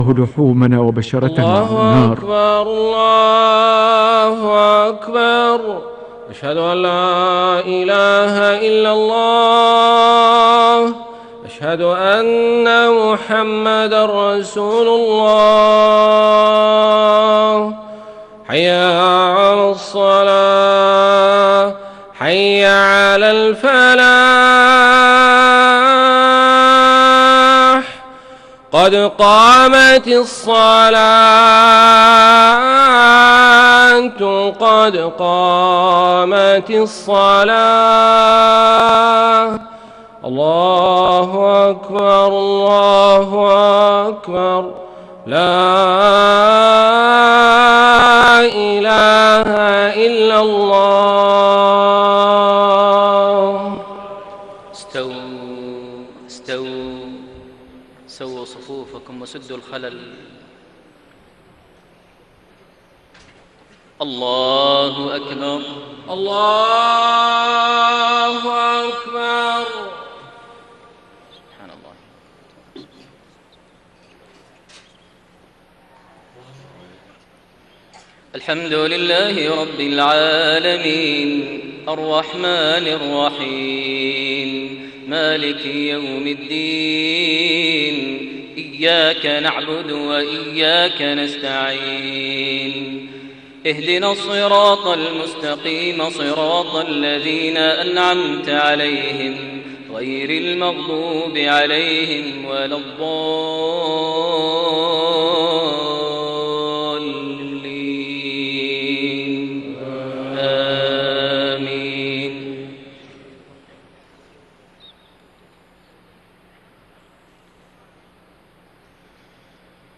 صلاة المغرب 16 ذو القعدة 1432هـ سورة البروج > 1432 هـ > الفروض - تلاوات ماهر المعيقلي